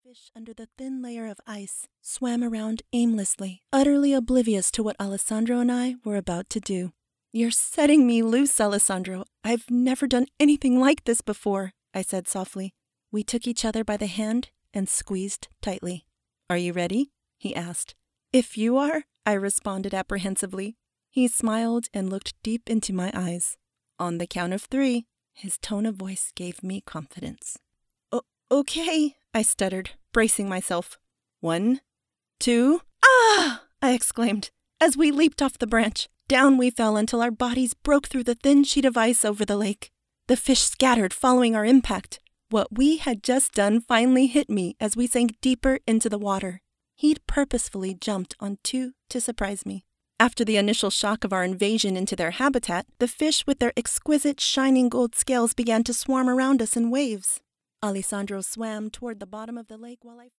First Encounter Audiobook